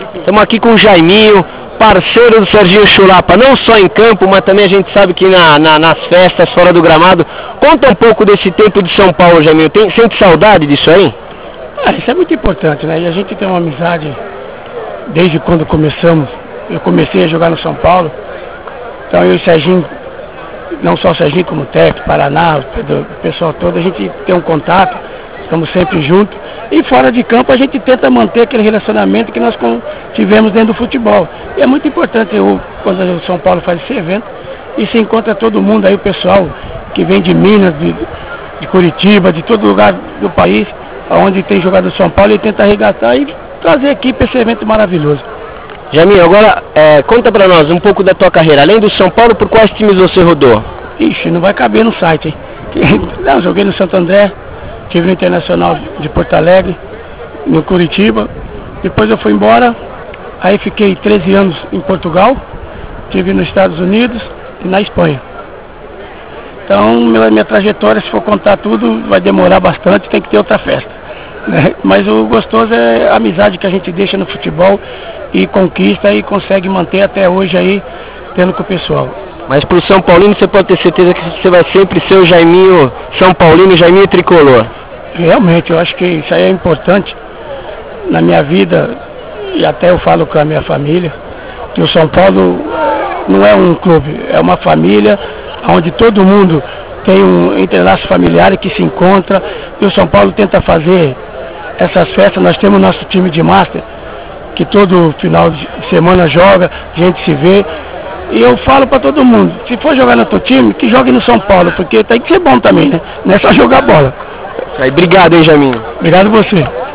A Equipe Tricolormania esteve presente no IV Encontro de ex-jogadores, fotografando e entrevistando alguns craques que fizeram história com a camisa São-paulina.